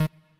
left-synth_chord_last51.ogg